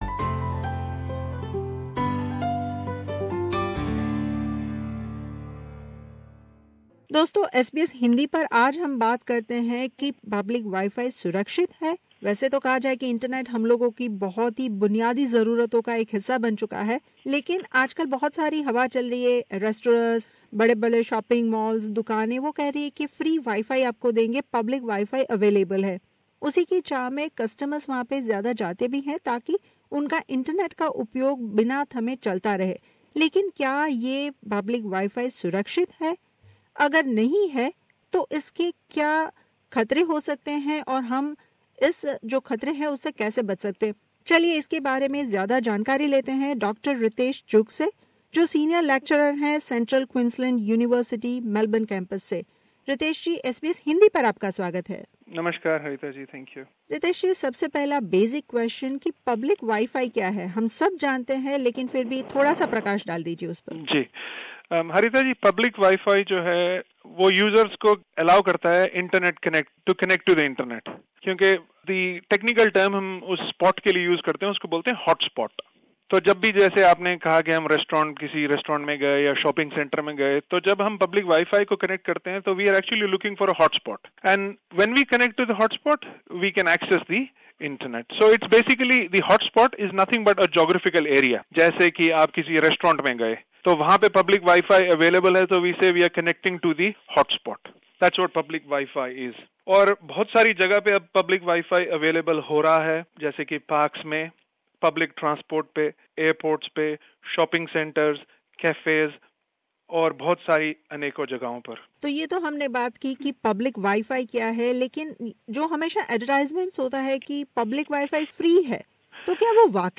Public Wi-Fi is becoming increasingly popular but its safety concerns remain an issue. In this interview